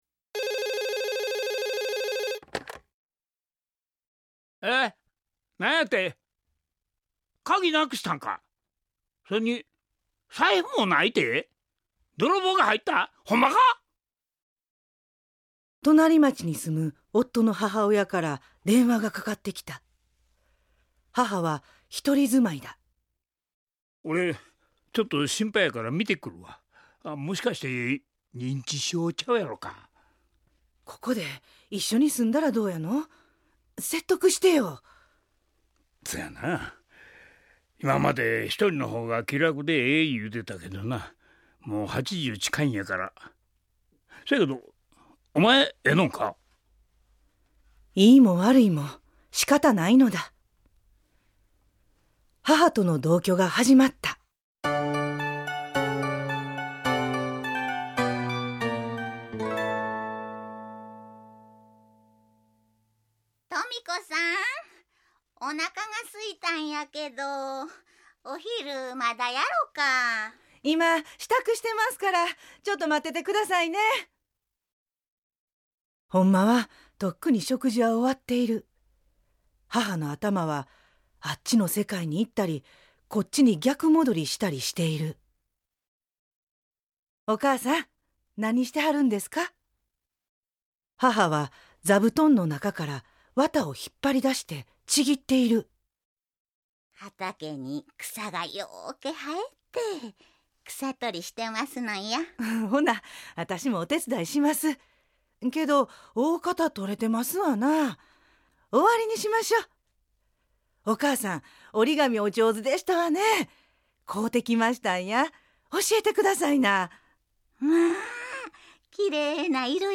●ラジオドラマ「LIFE」